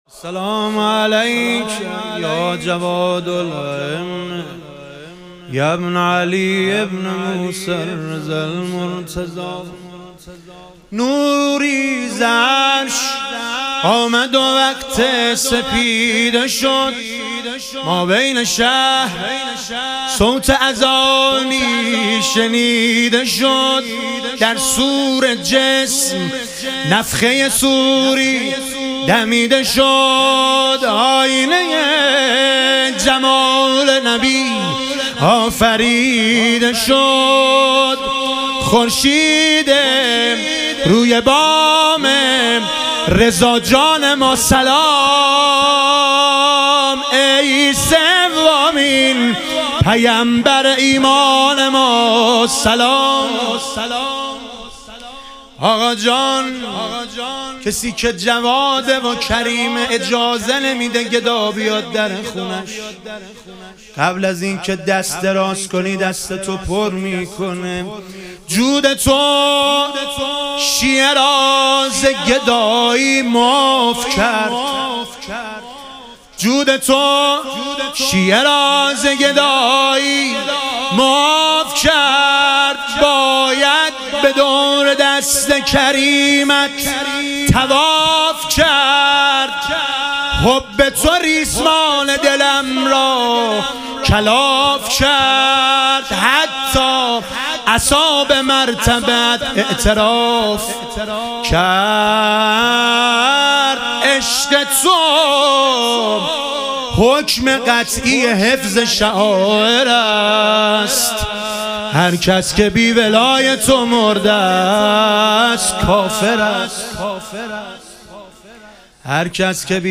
ظهور وجود مقدس امام جواد و حضرت علی اصغر علیهم السلام - مدح و رجز